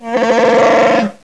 Grunts3 (27 kb)